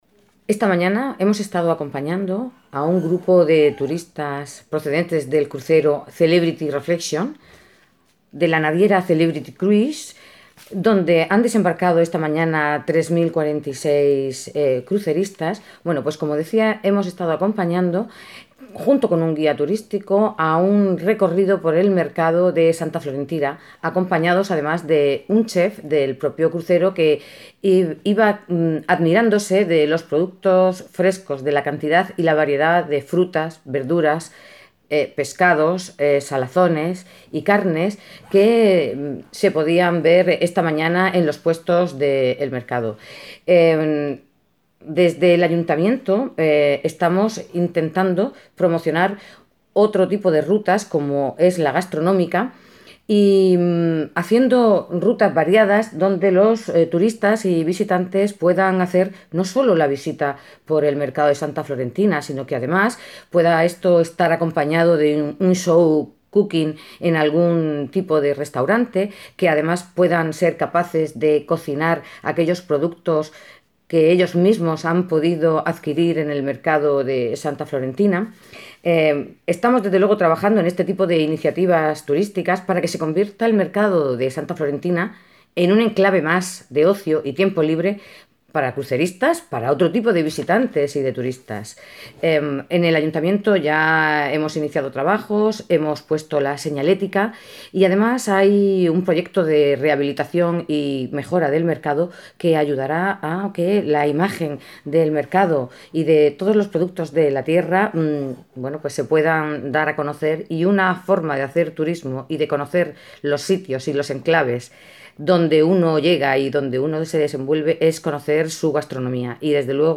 La concejala de Turismo, Obdulia Gómez, ha comentado las medidas en las que están trabajando para revitalizar a nivel comercial y turístico la plaza de abastos